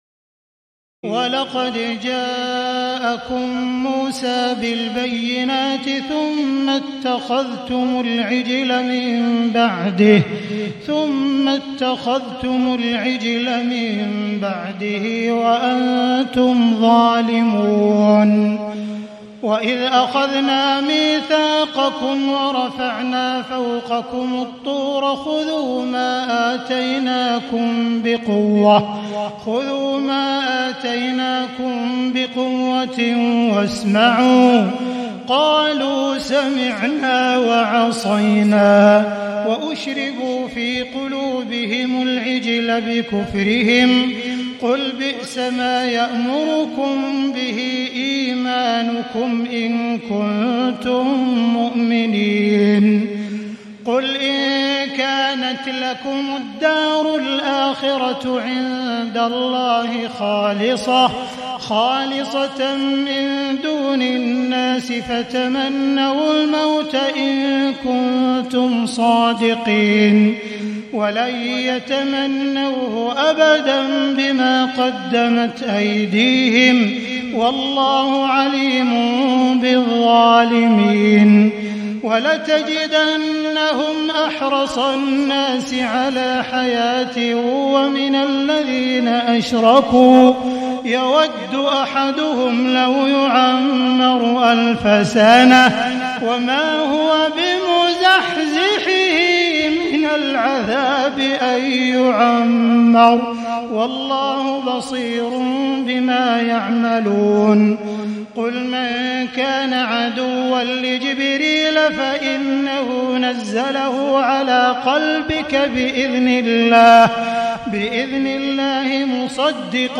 تهجد ليلة 21 رمضان 1437هـ من سورة البقرة (92-141) Tahajjud 21 st night Ramadan 1437H from Surah Al-Baqara > تراويح الحرم المكي عام 1437 🕋 > التراويح - تلاوات الحرمين